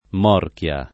morchia [ m 0 rk L a ] s. f.